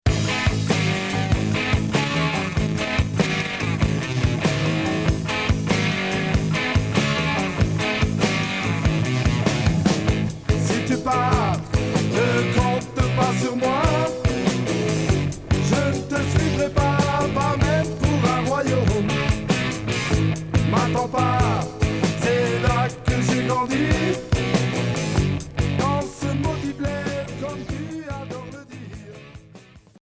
Hard progressif Premier 45t